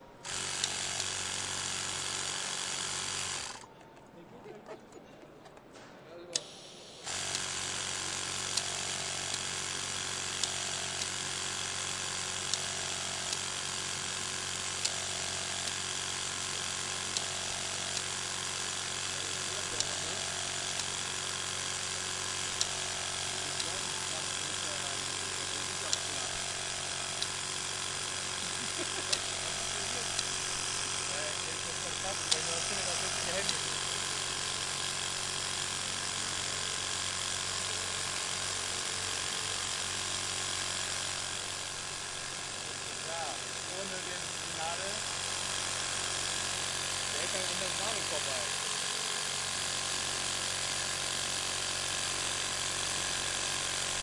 机场/飞机 " 机场公告
描述：汉诺威机场
Tag: 机场 公告 信息 演讲 现场录音